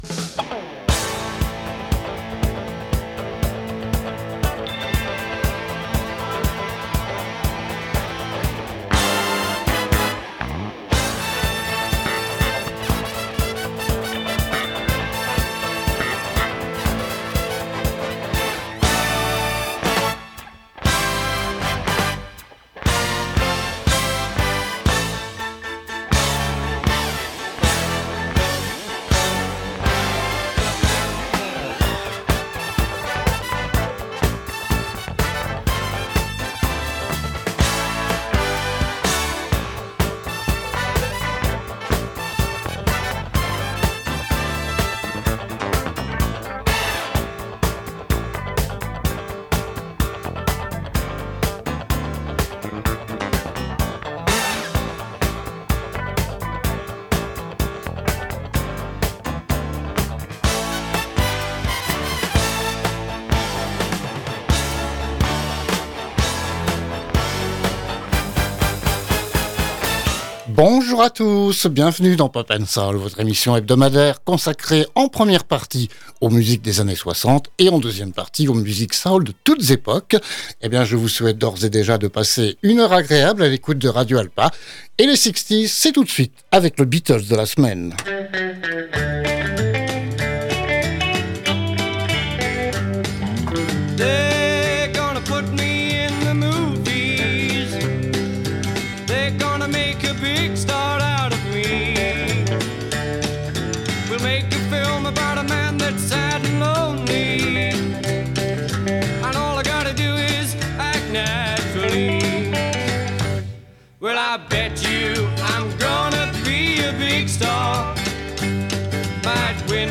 POP & SOUL - Radio Alpa
pop-soul.mp3